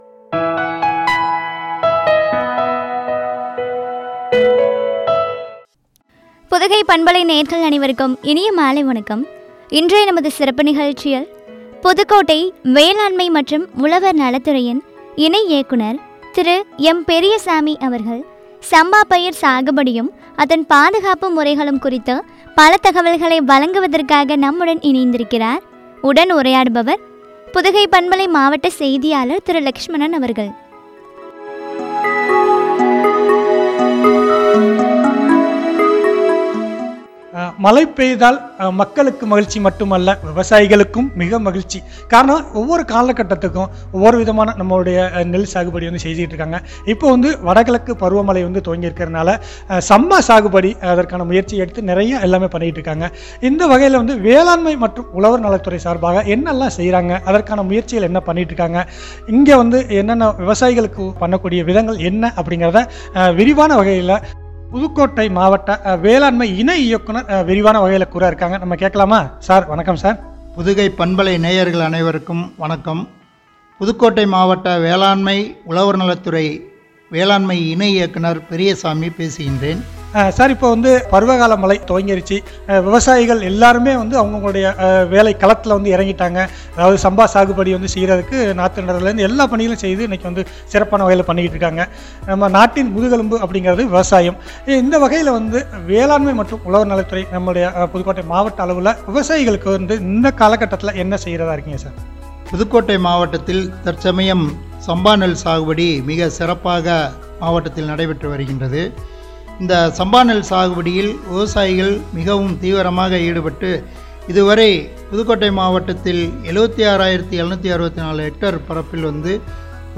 பாதுகாப்பு முறைகளும் பற்றிய உரையாடல்.